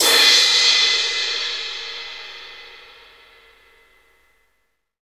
Index of /90_sSampleCDs/Roland - Rhythm Section/CYM_FX Cymbals 1/CYM_Cymbal FX
CYM BRUSH02L.wav